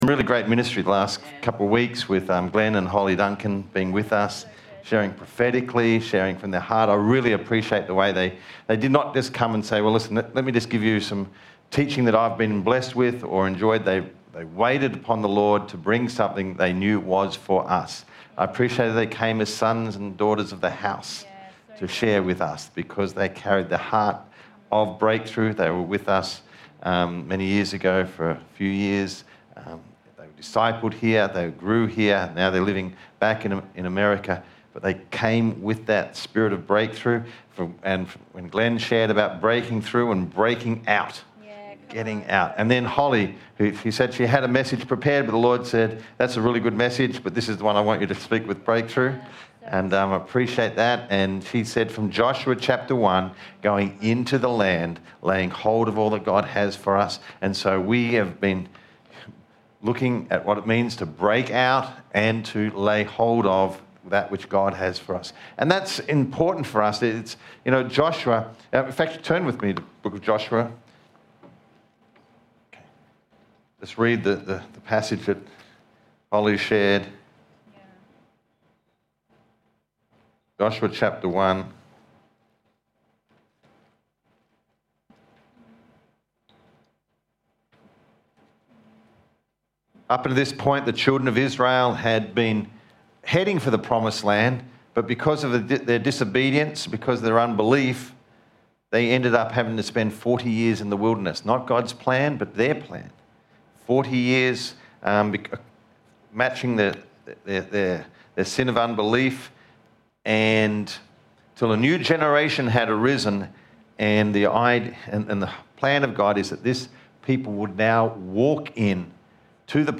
19 April, 2026 | Breakthrough Church, Melbourne, Australia